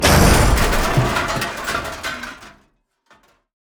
crash2.wav